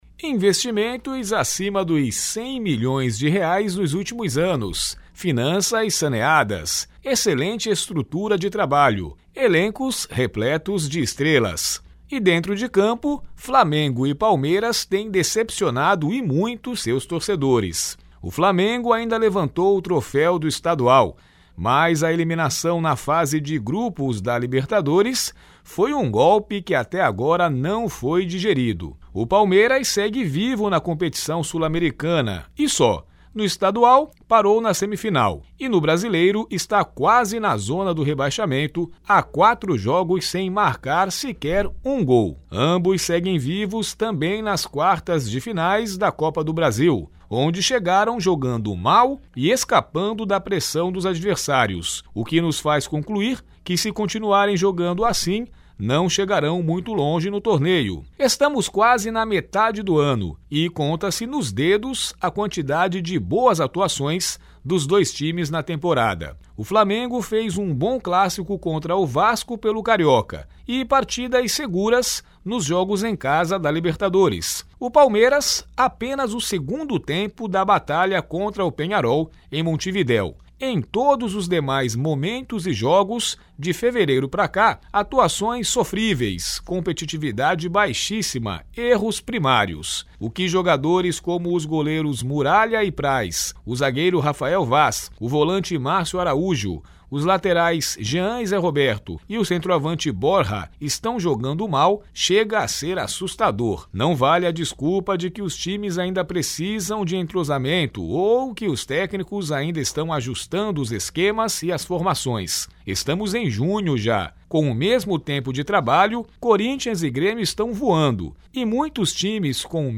comentario-esportivo.mp3